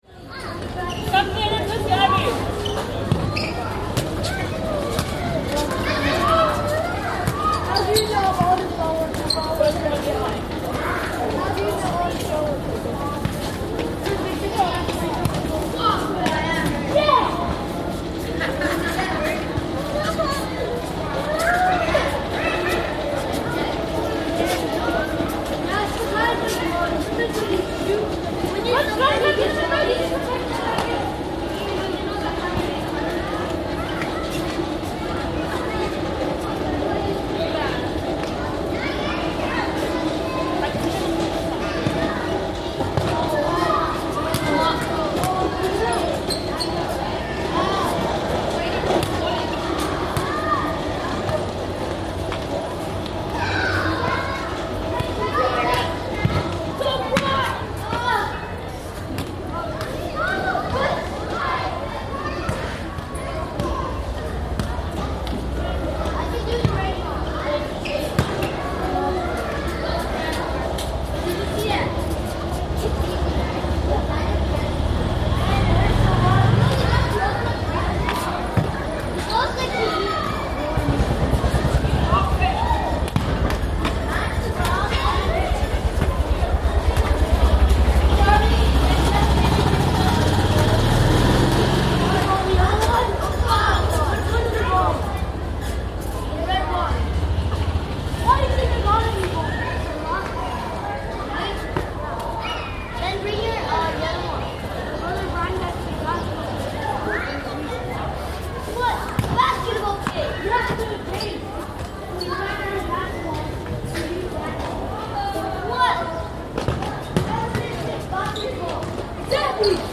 Yung Shue Wan playground
A recording taken which captures a moment of life away from the intensity that Hong Kong is associated with. Lamma Island is a short boat ride away and has a unique calm that is almost unimaginable when you've spent several weeks in the nearby city.
The sounds of children playing are atmospheric and contrasting against passing vehicles, and occasional sounds of wildlife.